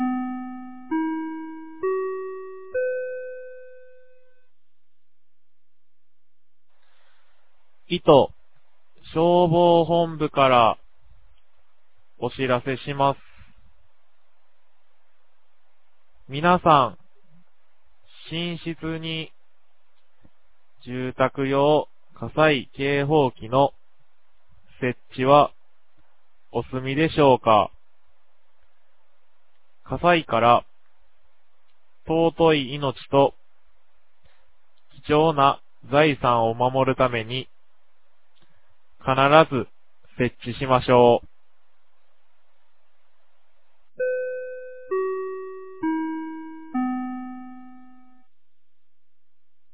2023年09月11日 10時06分に、九度山町より全地区へ放送がありました。